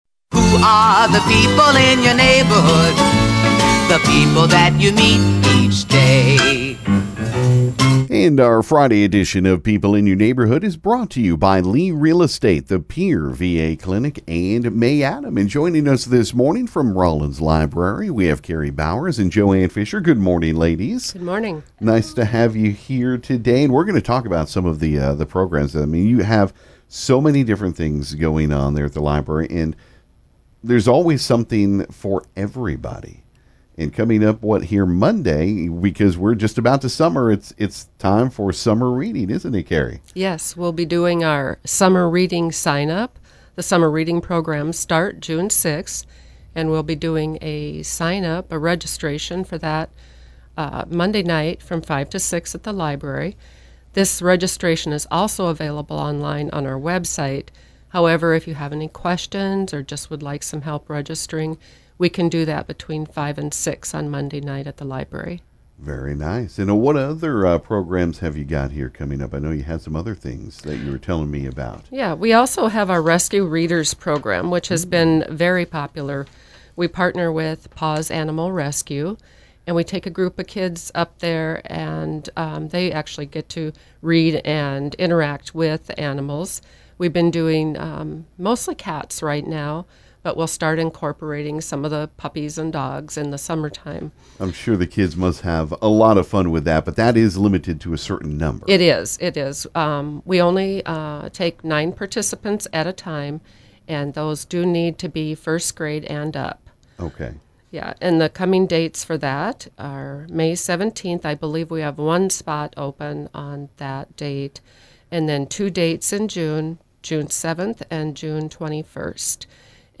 This morning at the KGFX studio